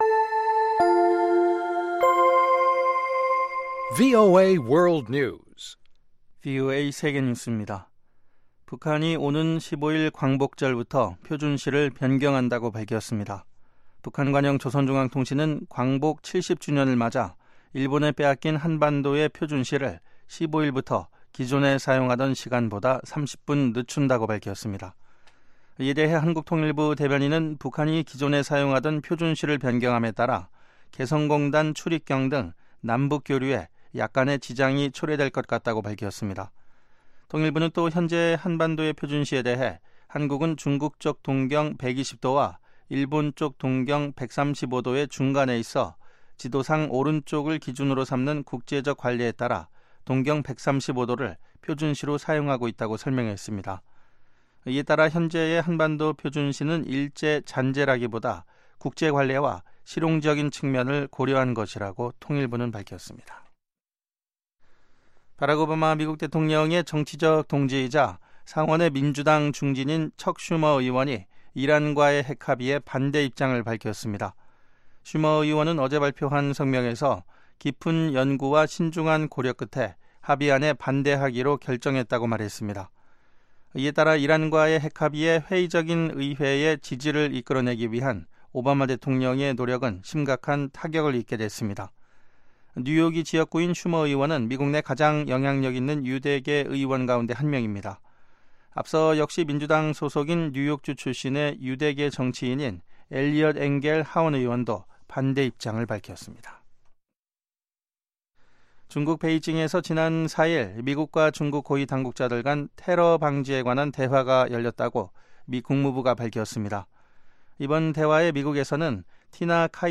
VOA 한국어 방송의 간판 뉴스 프로그램 '뉴스 투데이' 2부입니다. 한반도 시간 매일 오후 9시부터 10시까지 방송됩니다.